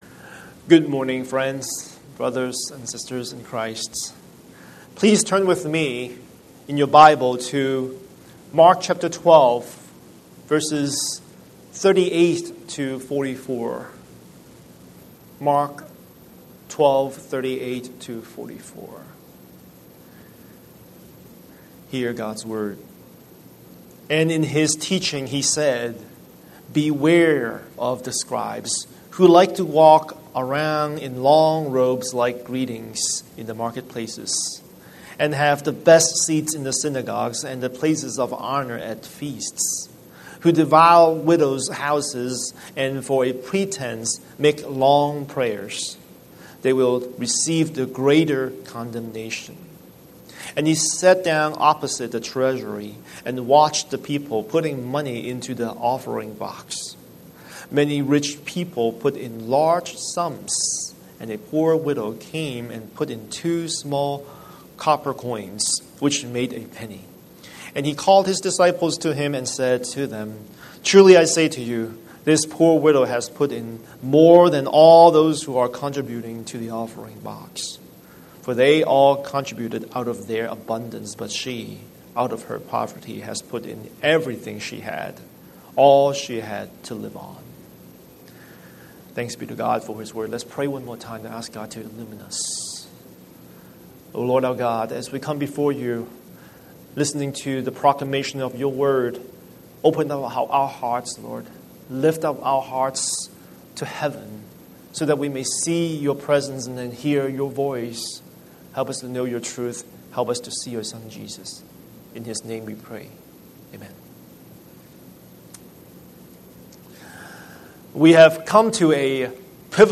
Scripture: Mark 12:38-44 Series: Sunday Sermon